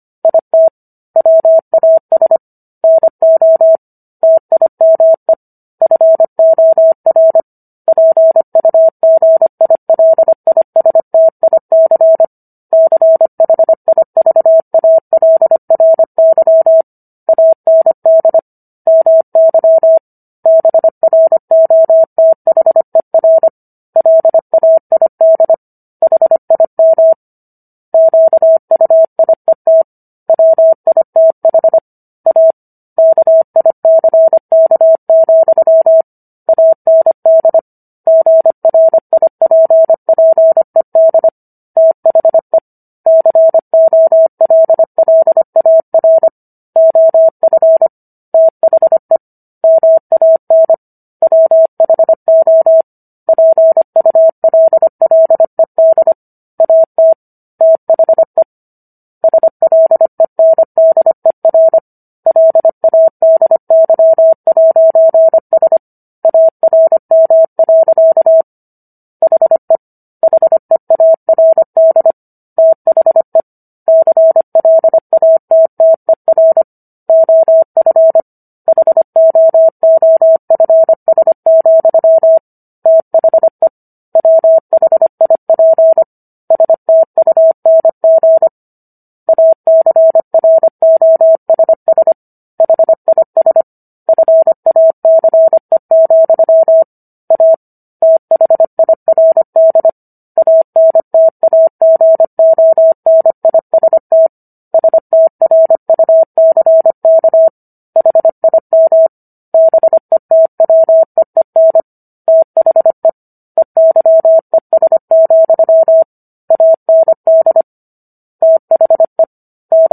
War of the Worlds - 16-Chapter 16 - 25 WPM